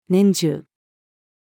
年中-female.mp3